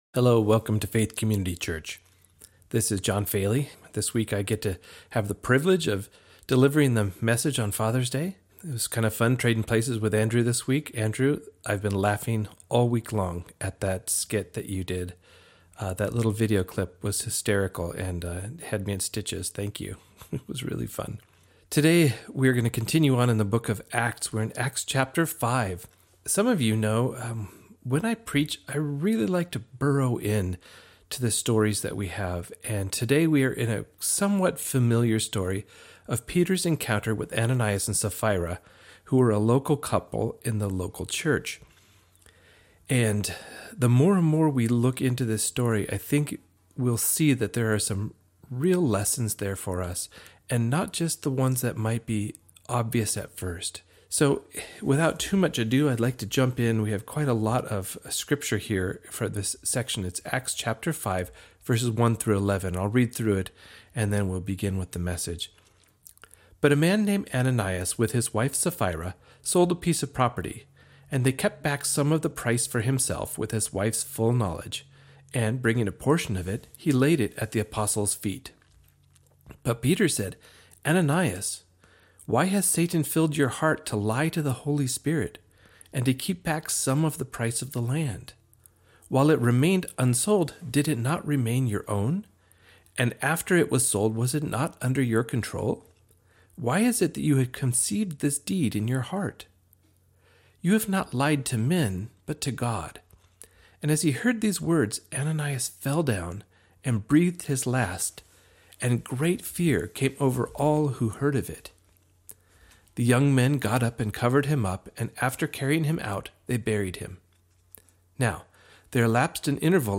2020-06-21 Sunday Service Guest Speaker